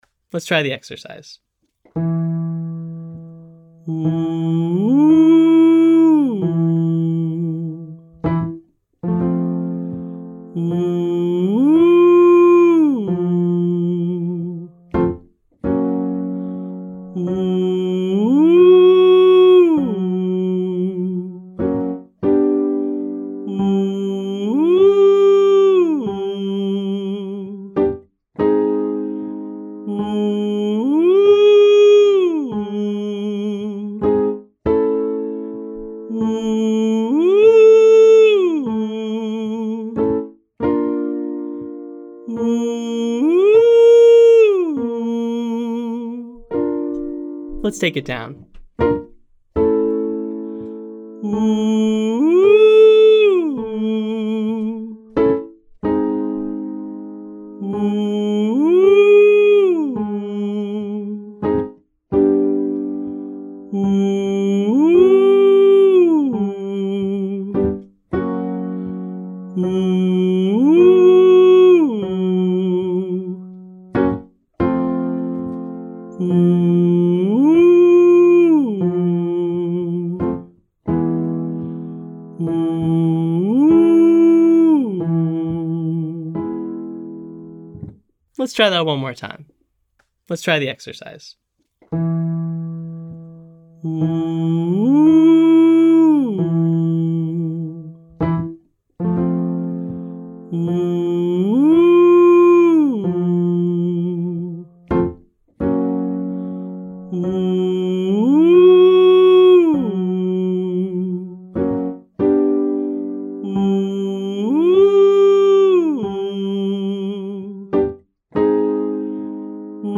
Begin with a narrow vowel like OO or EE, sliding one octave up and down.